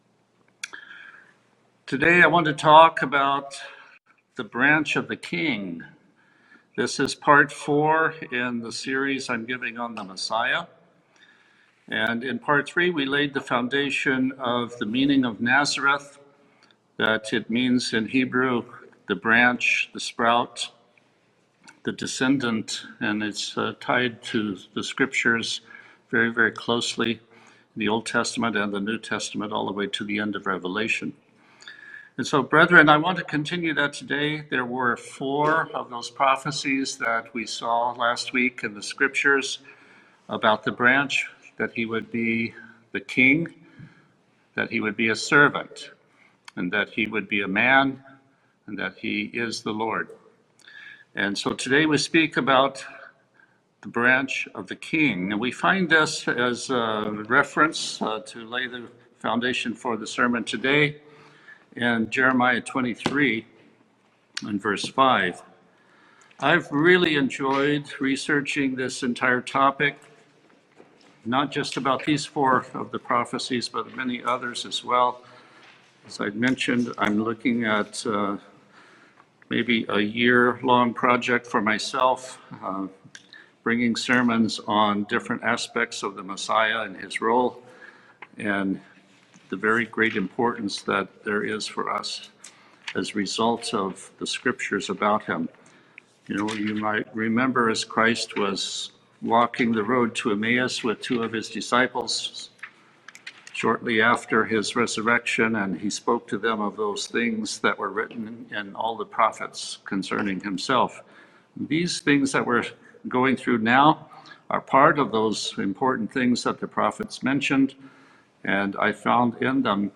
Given in Olympia, WA Tacoma, WA